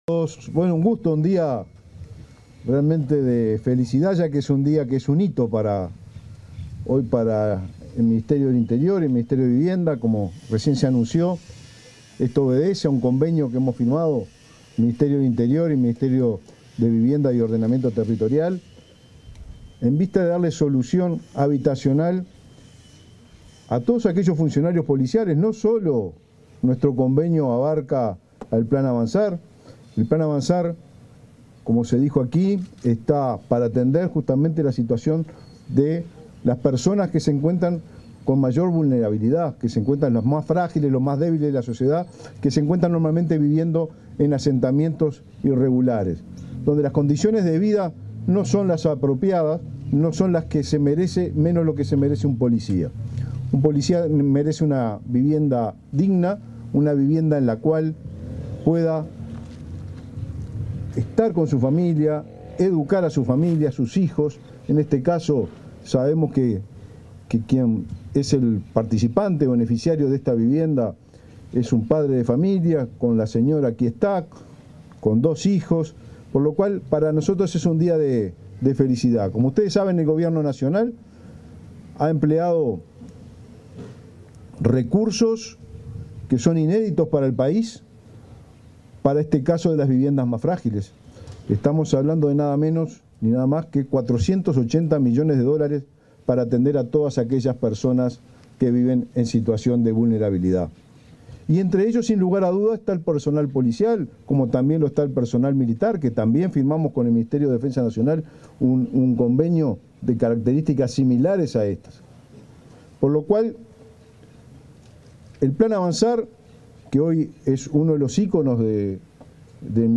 Palabras de los ministros del Interior y de Vivienda y Ordenamiento Territorial
Palabras de los ministros del Interior y de Vivienda y Ordenamiento Territorial 04/06/2024 Compartir Facebook X Copiar enlace WhatsApp LinkedIn En el marco de la entrega de la primera vivienda por convenio, este 4 de junio, se expresaron el ministro de Vivienda y Ordenamiento Territorial, Raúl Lozano, y el ministro del Interior, Nicolás Martinelli.
lozano acto .mp3